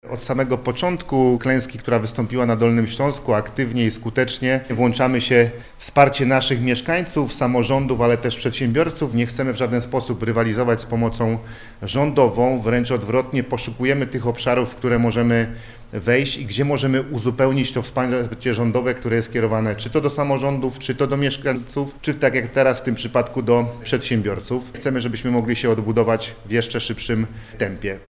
– mówi Paweł Gancarz, marszałek Województwa Dolnośląskiego.
01_marszalek.mp3